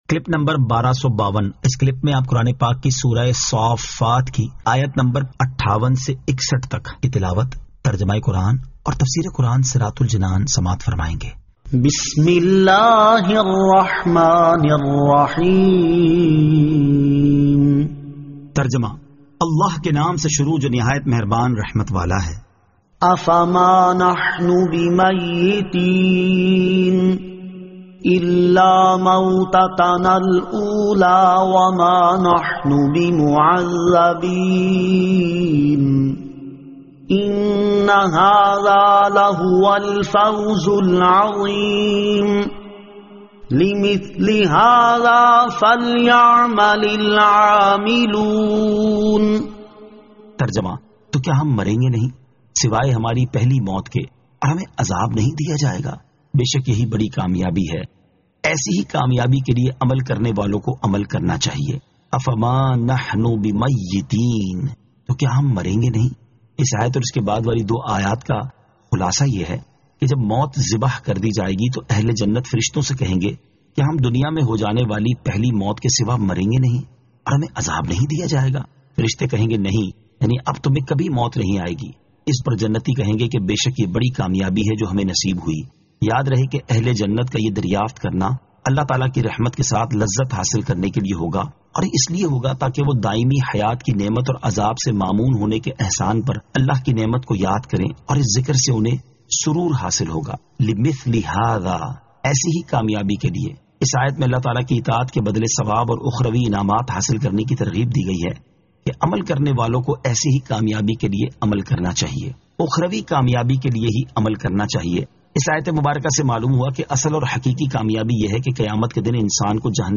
Surah As-Saaffat 58 To 61 Tilawat , Tarjama , Tafseer